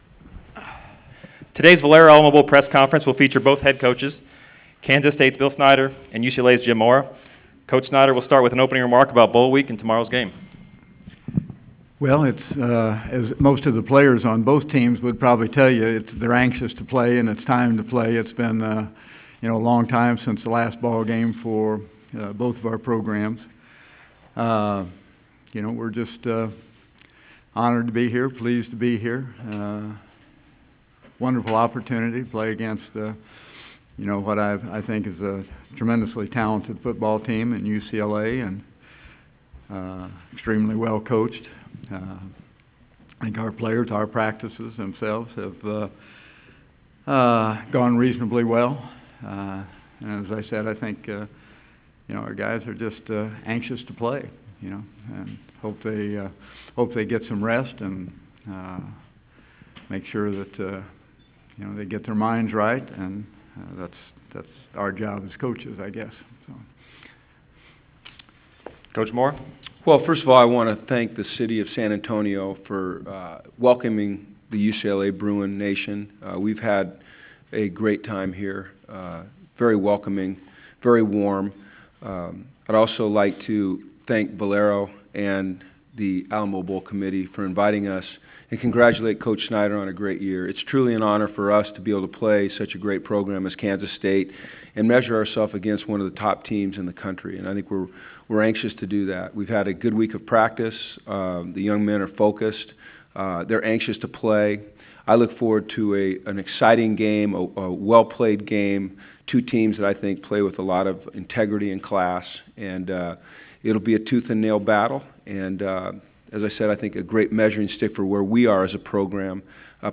Head Coaches Press Conference Pt. 1
Kansas State head coach Bill Snyder and UCLA head coach Jim Mora.
ABowl-2014-Coaches-PC-Pt1.wav